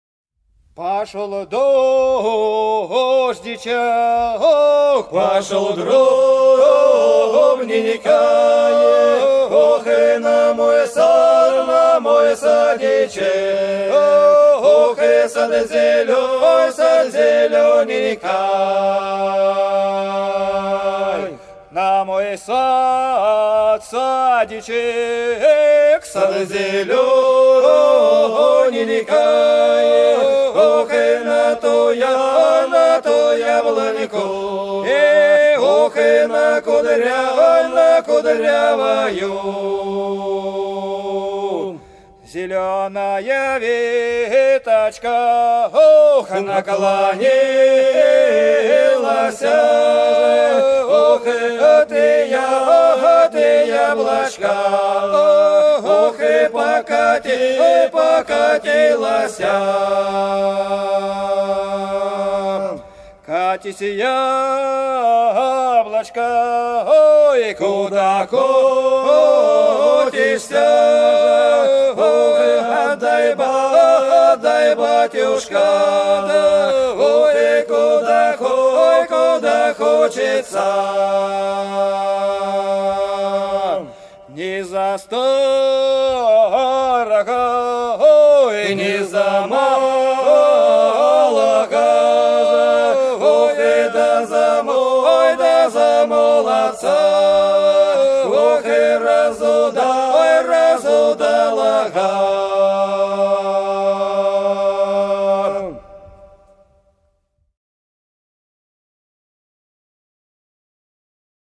Долина была широкая (Поют народные исполнители села Нижняя Покровка Белгородской области) Пошел дождичек - протяжная